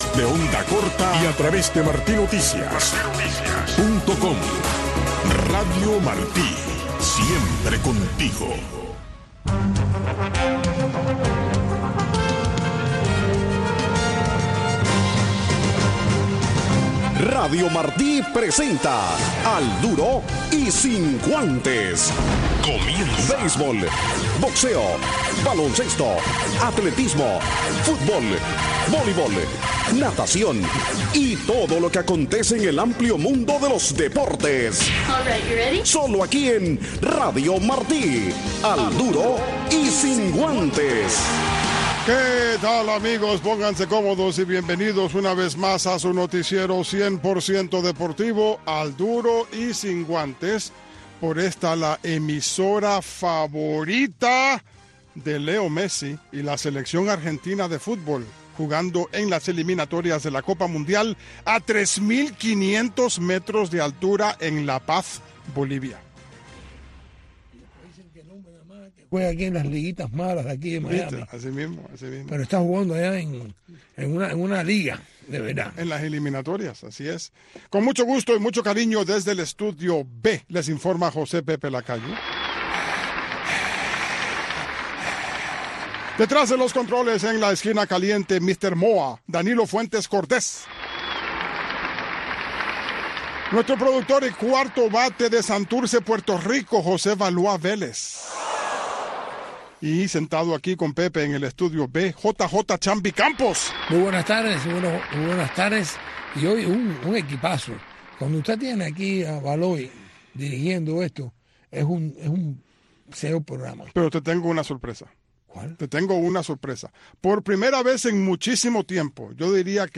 Un resumen deportivo en 60 minutos conducido por